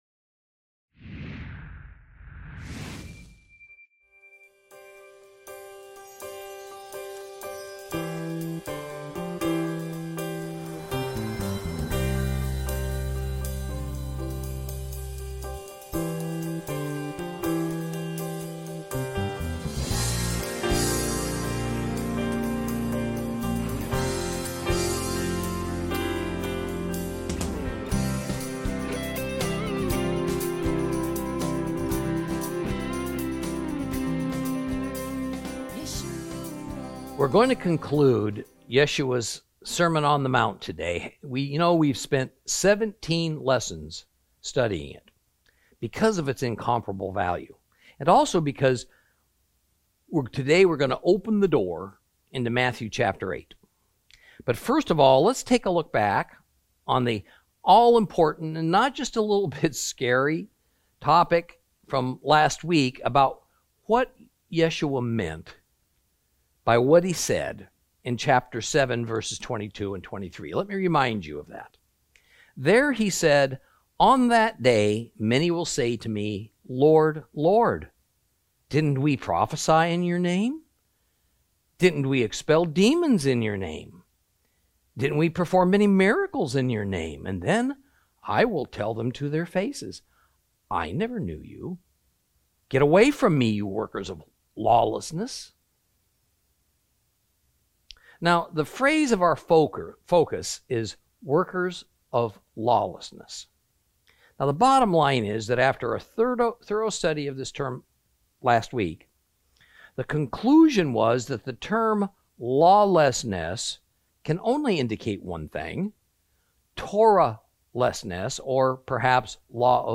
Lesson 27 Ch7 Ch8